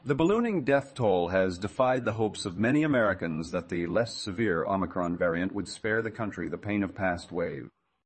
tortoise-tts - (A fork of) a multi-voice TTS system trained with an emphasis on quality